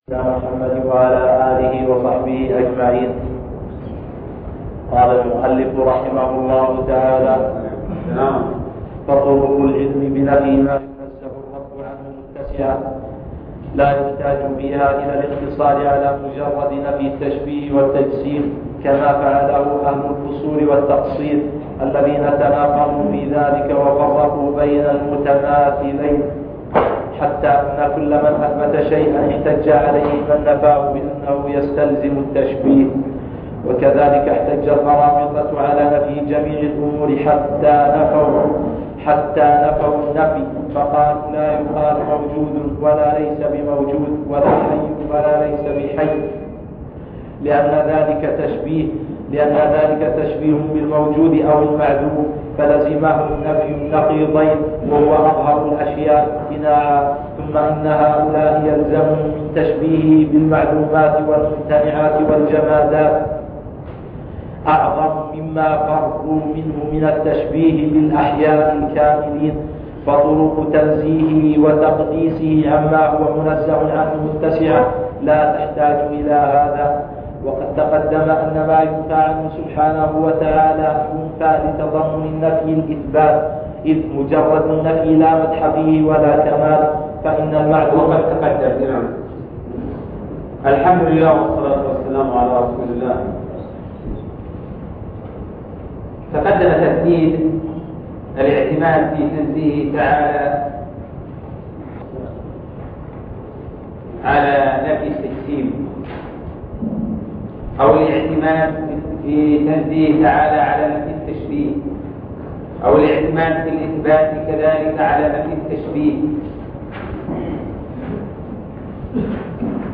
عنوان المادة شرح الرسالة التدمرية (33) الدرس الثالث والثلاثون تاريخ التحميل السبت 19 فبراير 2022 مـ حجم المادة 40.18 ميجا بايت عدد الزيارات 248 زيارة عدد مرات الحفظ 132 مرة إستماع المادة حفظ المادة اضف تعليقك أرسل لصديق